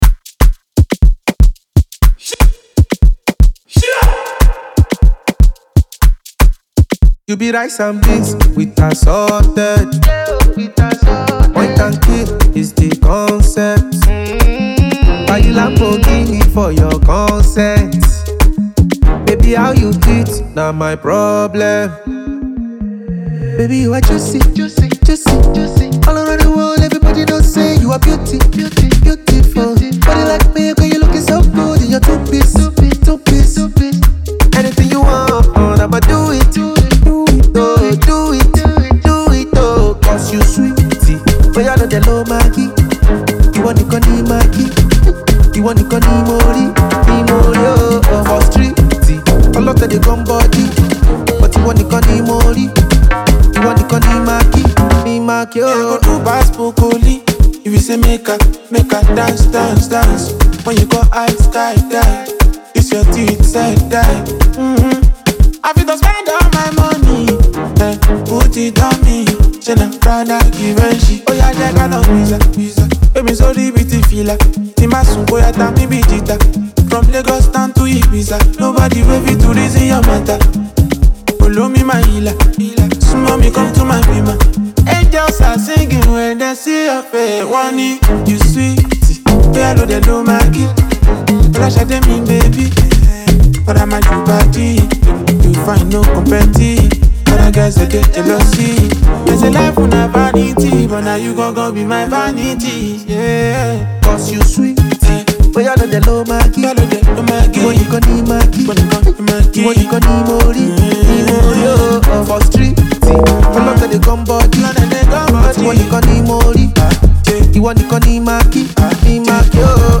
smooth Afrobeat sound